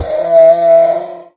sounds / mob / horse / donkey / death.mp3
death.mp3